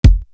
Soul Kick.wav